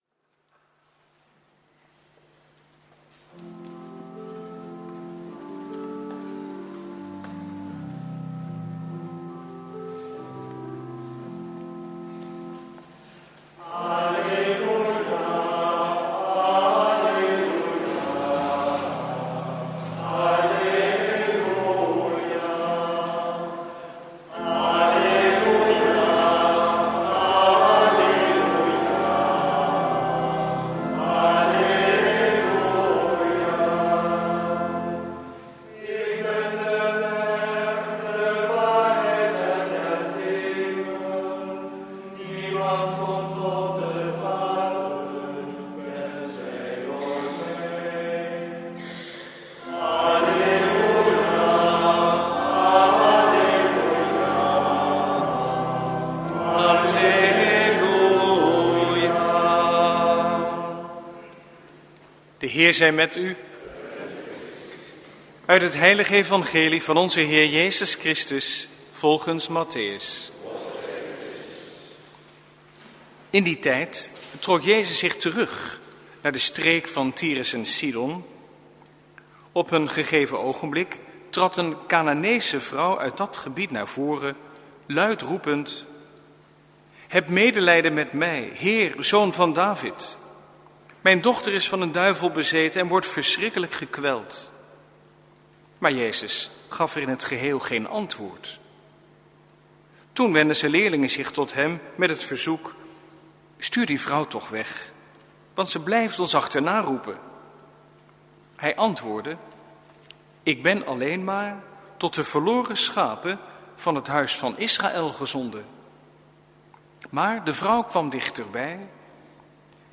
Preek 20e zondag, door het jaar A, 19/20 augustus 2017 | Hagenpreken